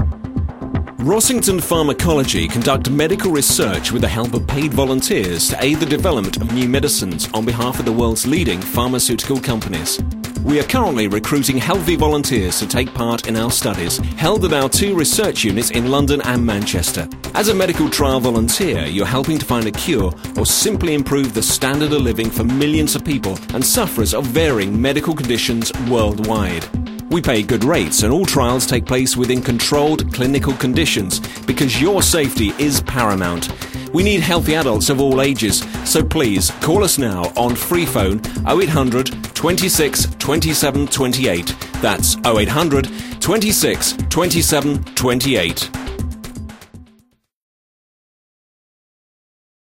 With twenty years of experience working in radio for the BBC and a rich, warm, cultured voice
Health Ad
06-Health-Ad_.mp3